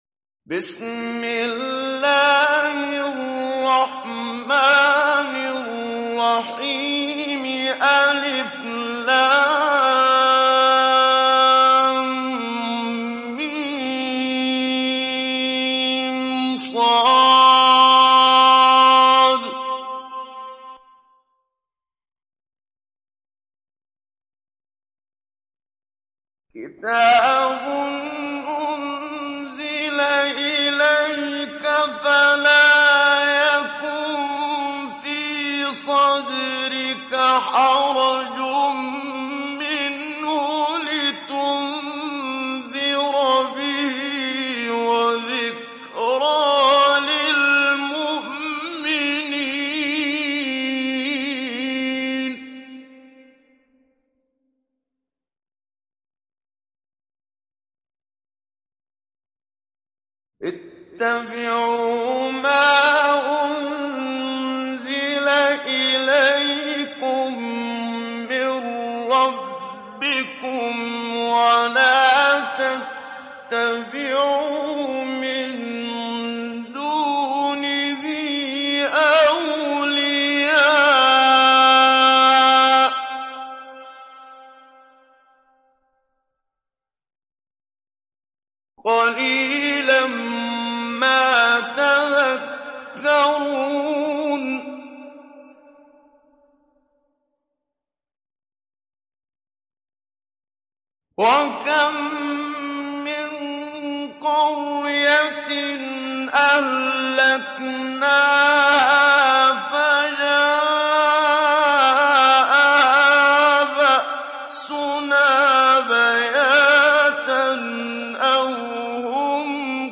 تحميل سورة الأعراف محمد صديق المنشاوي مجود